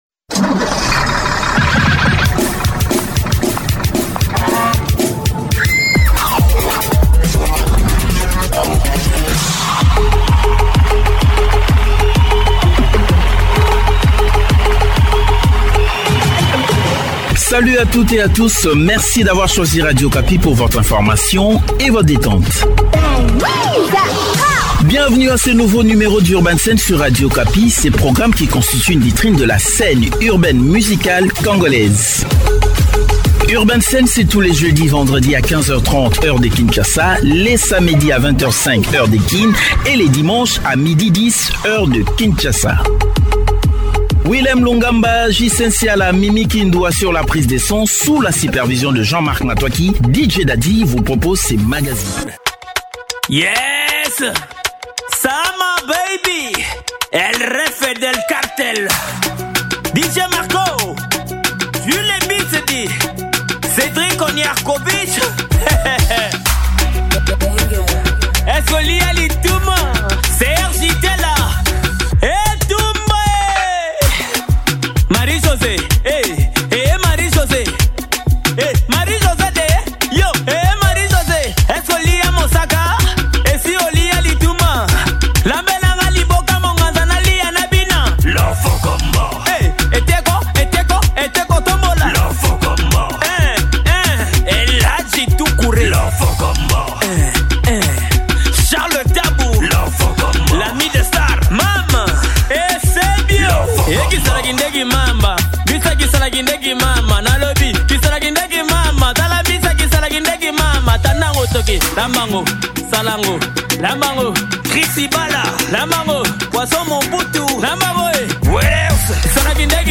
Découvrez quelques nouveaux single et tubes, ainsi que l'interview